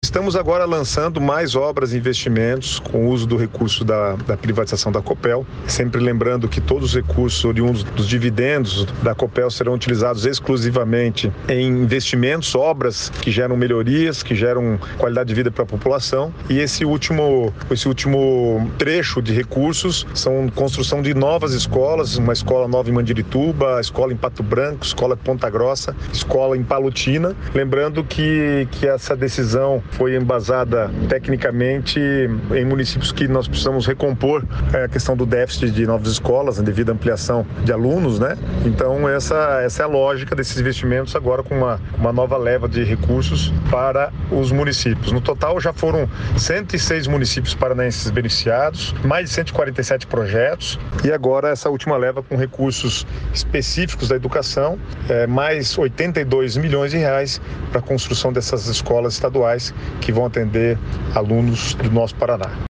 Sonora do secretário do Planejamento, Guto Silva, sobre o investimento em novas escolas com recursos da venda de ações da Copel